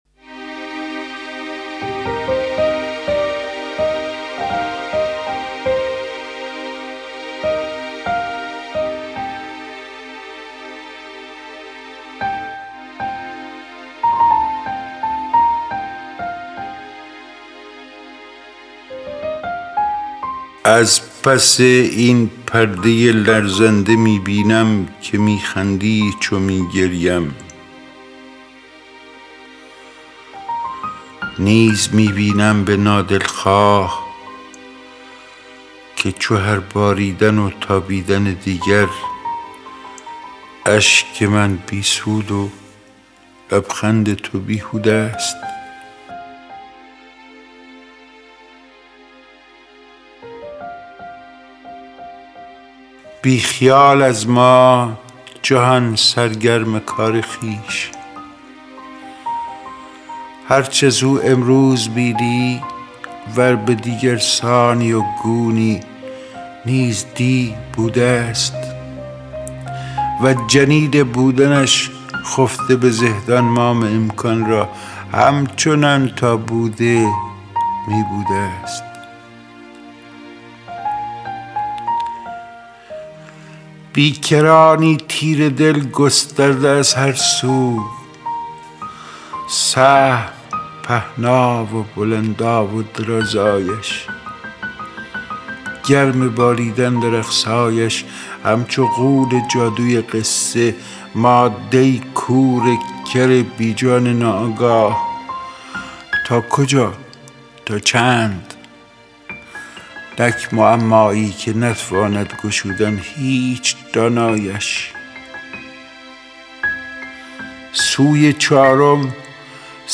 دانلود دکلمه از پس این پرده لرزنده می بینم با صدای اسماعیل خویی
گوینده :   [اسماعیل خویی]